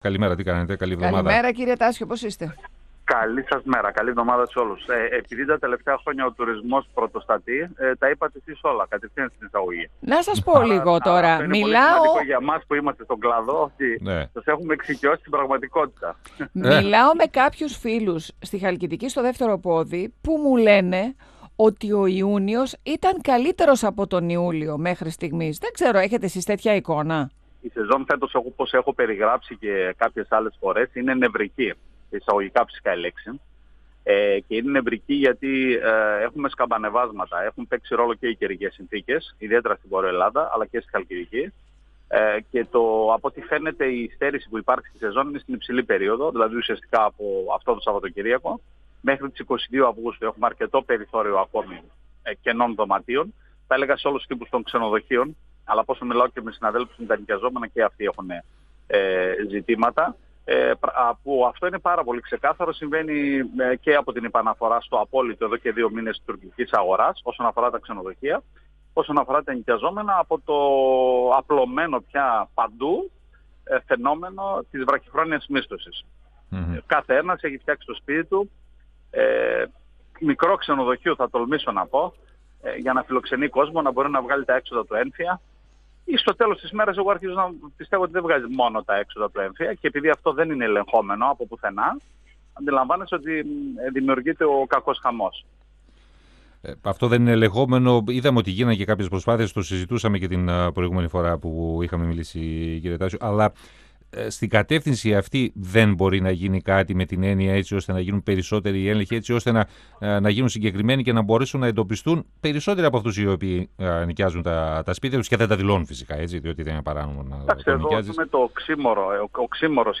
Συνέντευξη
στην πρωινή ενημερωτική εκπομπή του 102fm